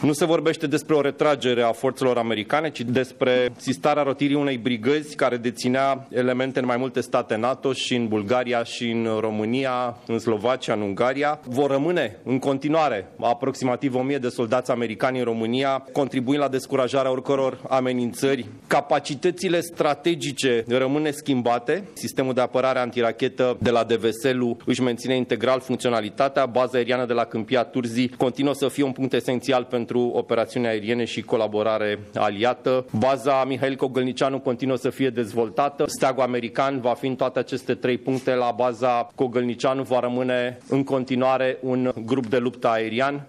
Ministrul Apărării Ionuţ Moşteanu dă asigurări că România rămâne o ţară sigură în care prezenţa aliată este considerabilă: